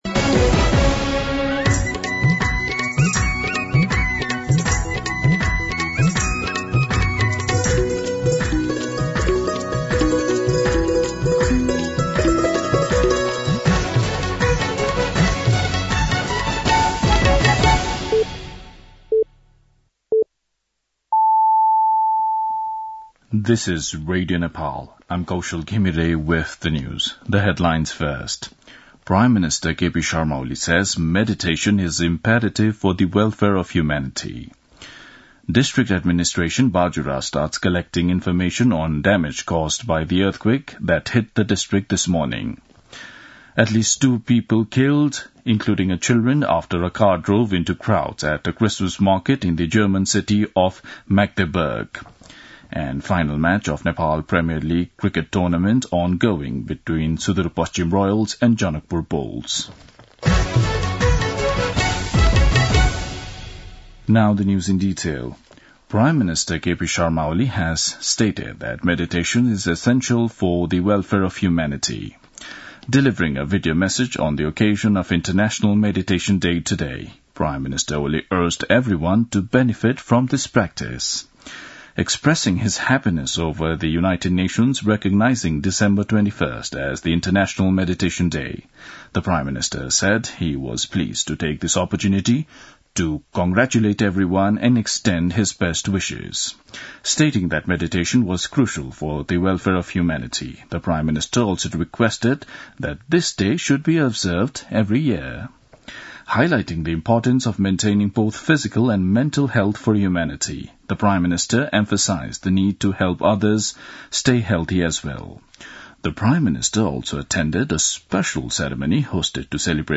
दिउँसो २ बजेको अङ्ग्रेजी समाचार : ७ पुष , २०८१
2-pm-English-News-2.mp3